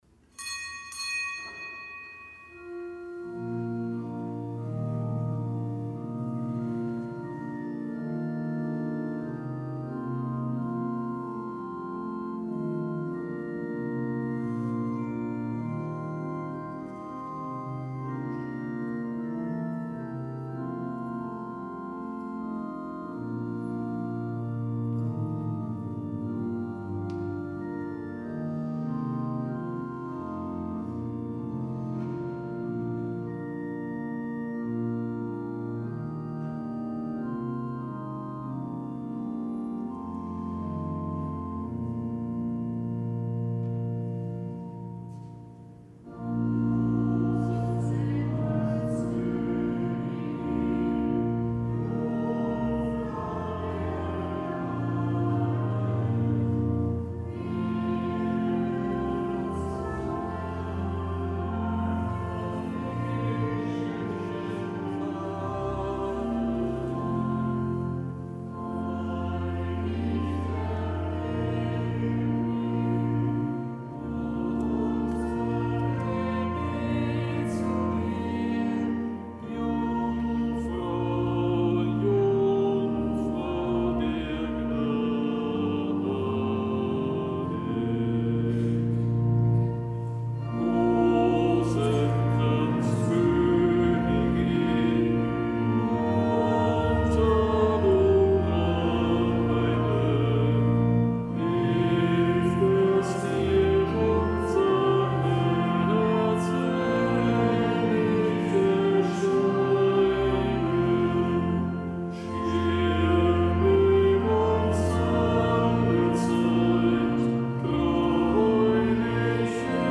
Kapitelsmesse aus dem Kölner Dom am Samstag der neunundzwanzigsten Woche im Jahreskreis, einem Marien-Samstag, der als nichtgebotener Gedenktag begangen wird.